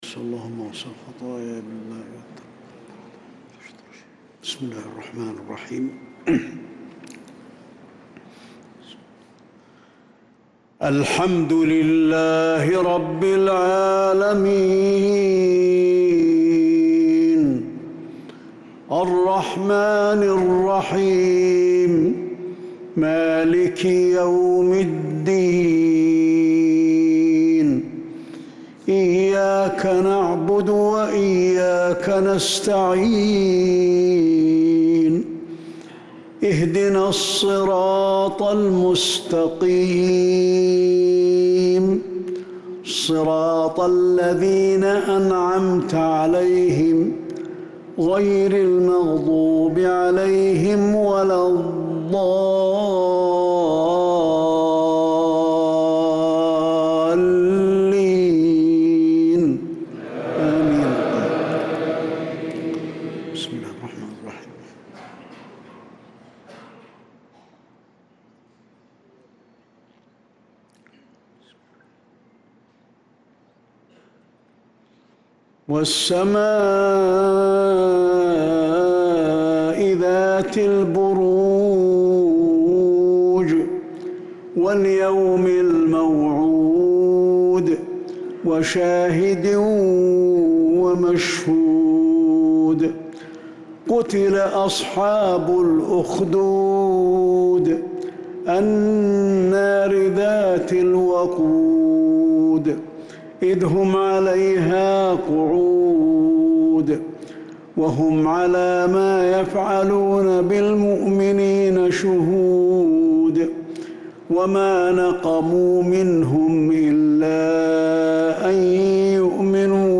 صلاة العشاء للقارئ علي الحذيفي 5 ذو القعدة 1445 هـ
تِلَاوَات الْحَرَمَيْن .